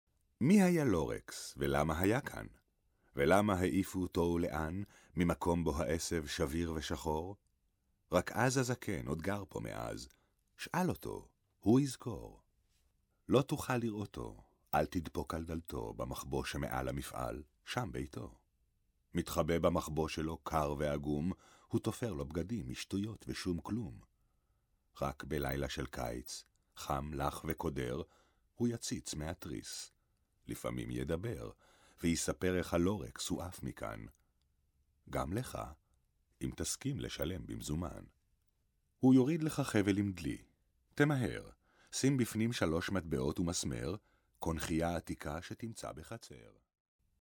Männlich